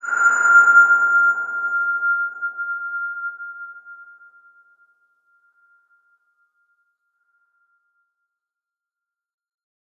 X_BasicBells-F4-pp.wav